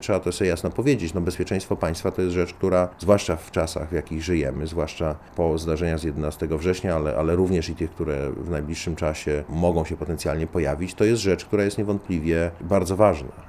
Posłuchaj, co powiedział wiceminister Heller (KB)